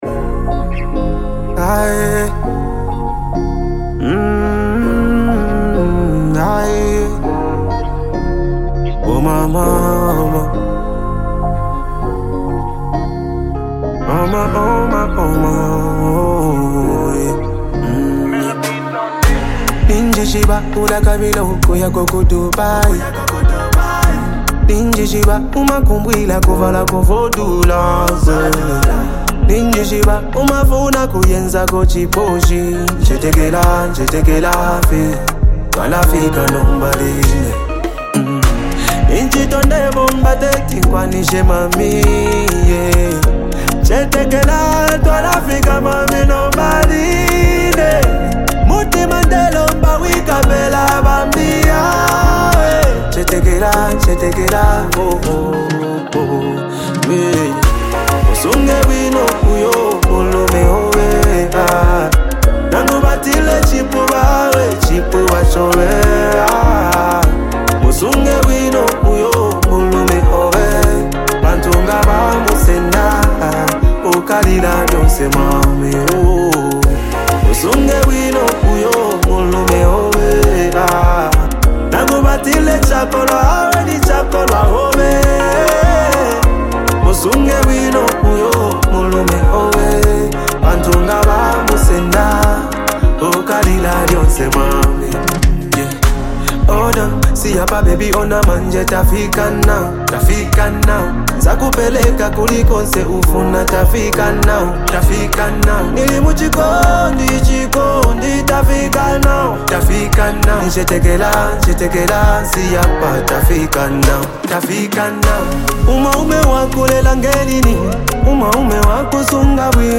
The fusion of Afro-pop and dance influences